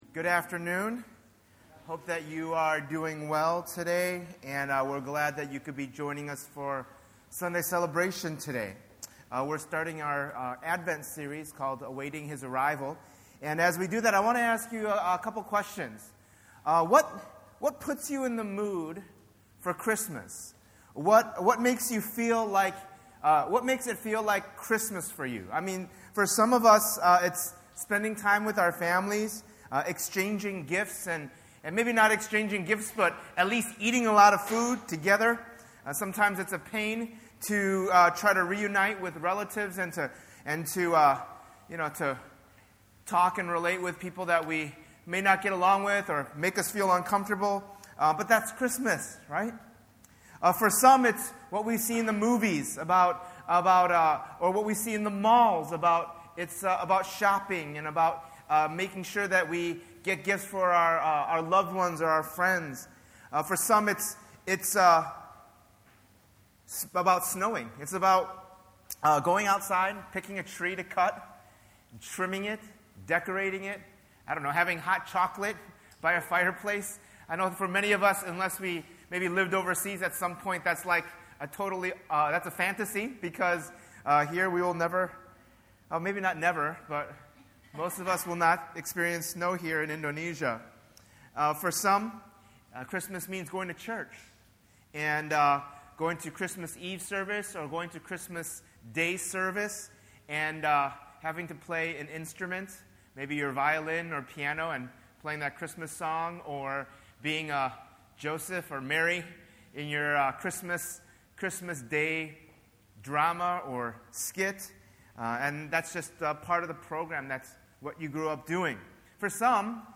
The Advent season is to commemorate Christ's arrival into our broken world. Throughout this sermon series, we’ll look into the Psalms and see how God’s people waited for a Savior.